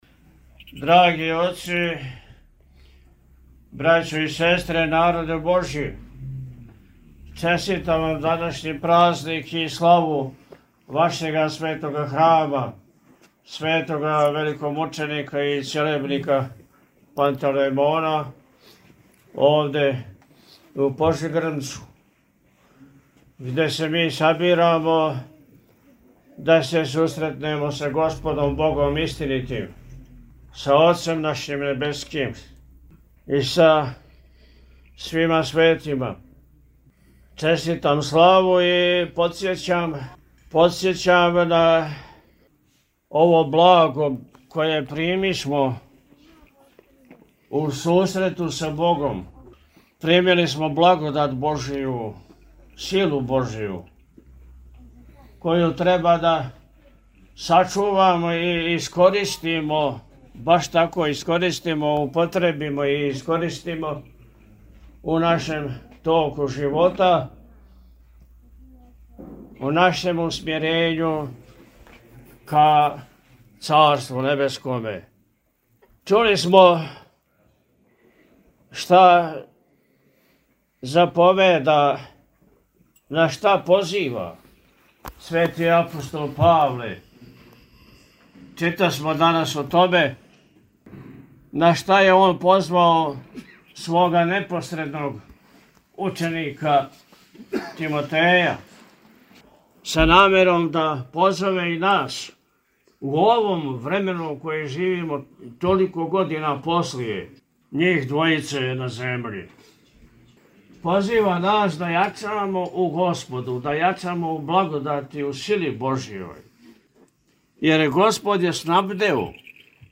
У Пожегрмцу код Прибоја прослављена храмовна слава - Eпархија Милешевска
Pozegrmac-Beseda.mp3